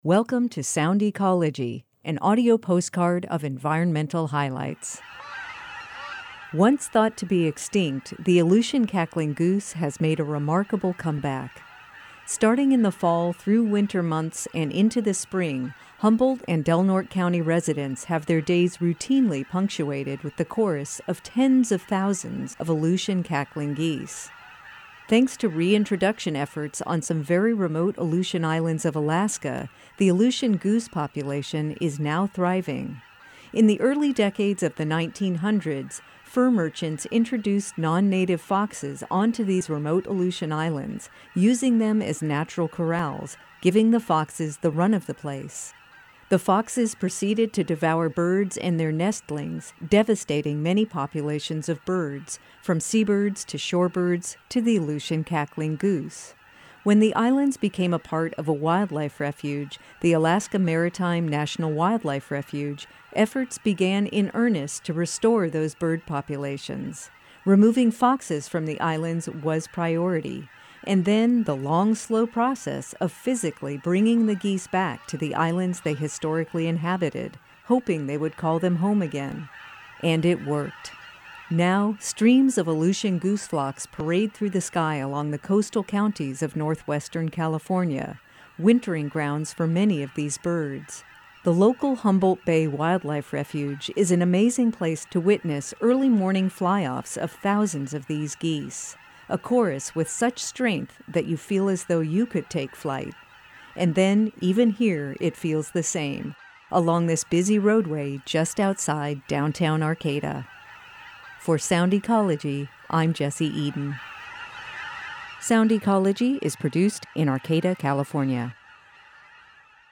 sound_ecology_aleutian_geese_0.mp3